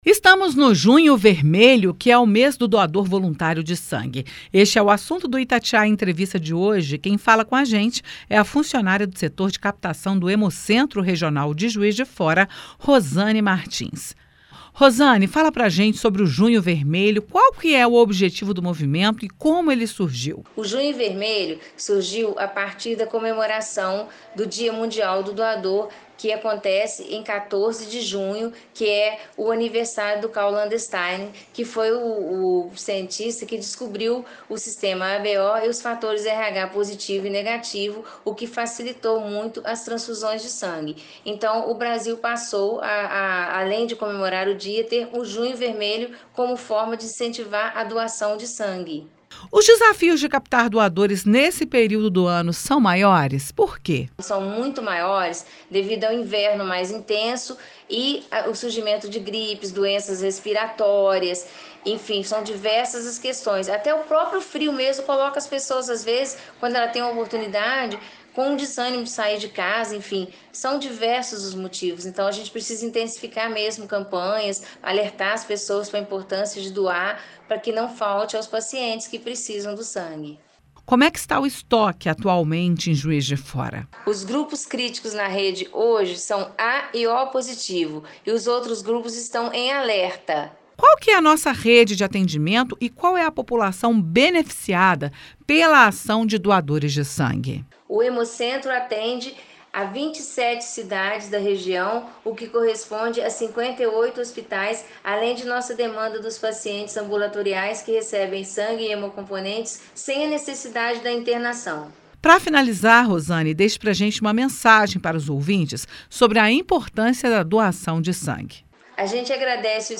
05.06_Itatiaia-Entrevista_Junho-Vermelho.mp3